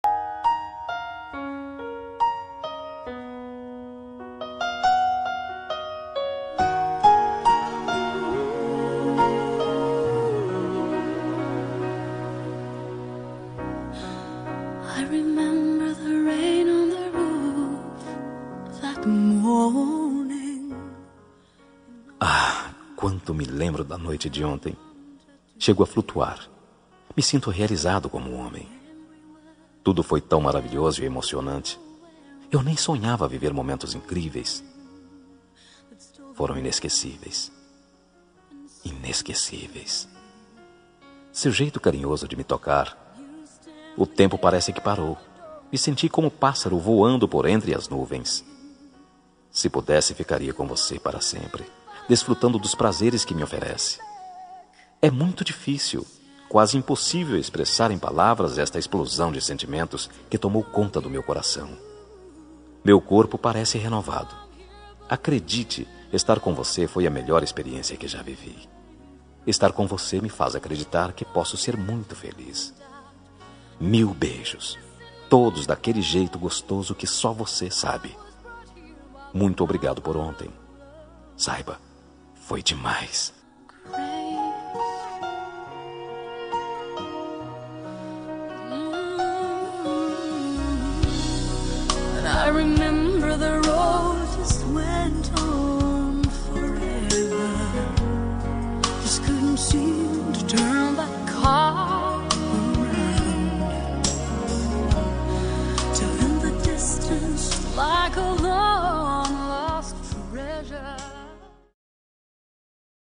Telemensagem Momentos Especiais – Voz Masculina – Cód: 201891 – Noite Maravilhos